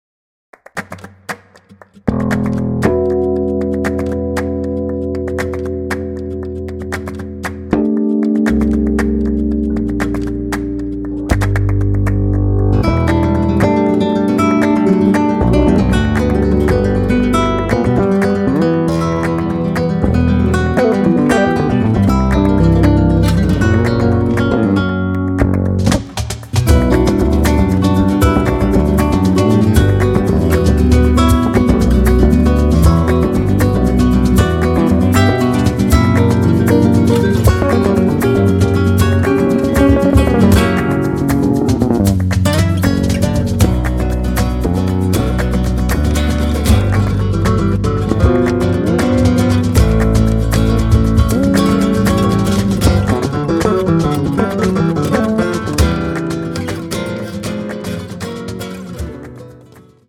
flamenco-gitarre, laúd, perc.
kontrabass, e-bass
percussion
mundharmonika